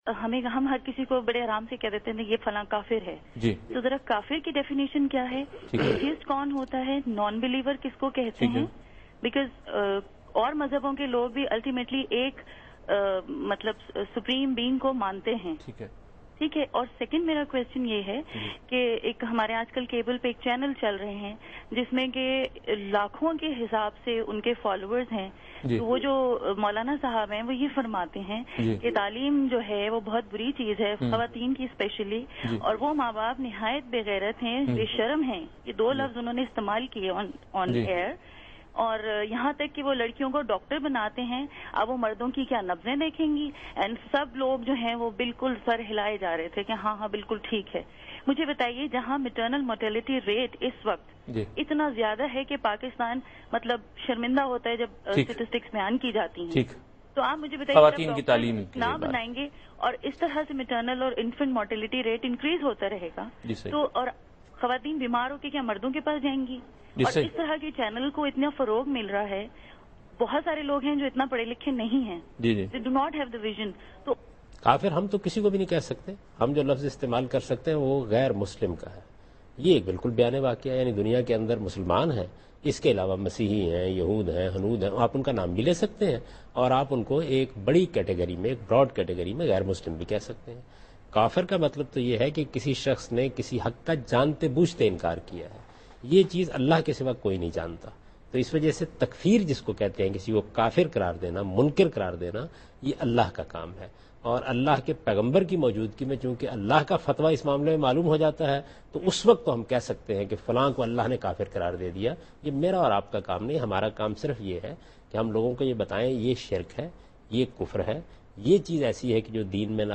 Category: TV Programs / Dunya News / Deen-o-Daanish /
Javed Ahmad Ghamidi answers a question regarding "Definition of a Kafir" in Dunya Tv's program Deen o Daanish.